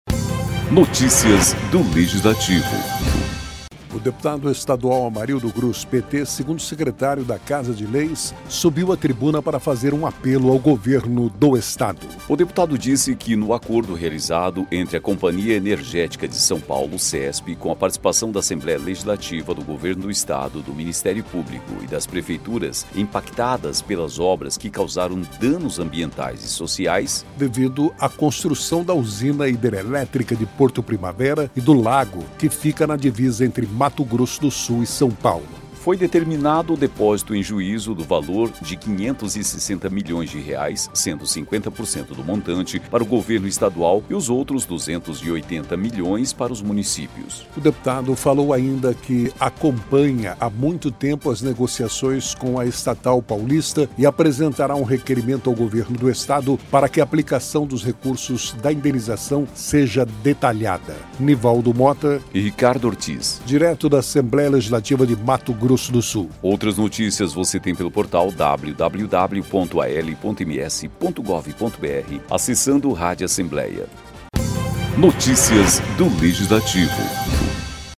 O deputado estadual Amarildo Cruz (PT), 2º secretário da Casa de Leis, subiu à tribuna para fazer um apelo ao Governo do Estado.